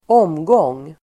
Uttal: [²'åm:gång:]